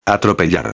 I- Escreva as palavras escutadas. Preste atenção no som das letras "LL" e "Y".